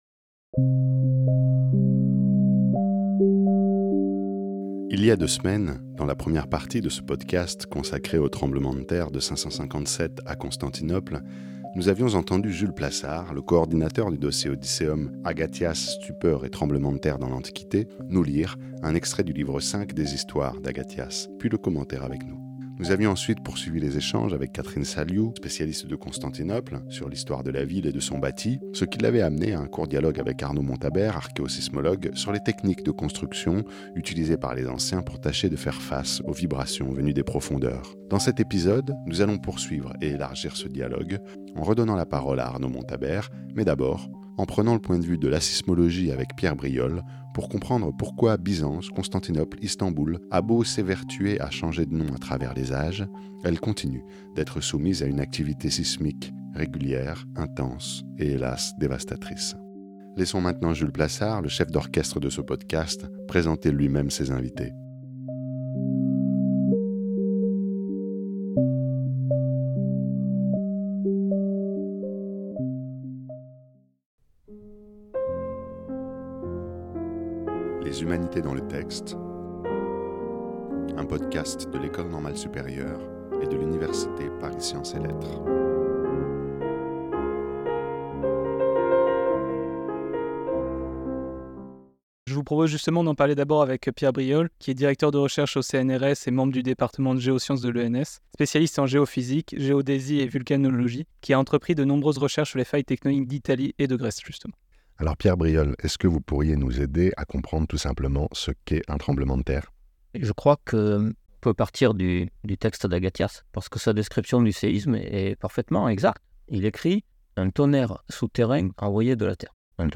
C'est ce que vous propose ce podcast avec l'intervention croisée d'historiens, d'archéologues et de sismologues et une lecture d'un extrait des Histoires d'Agathias, a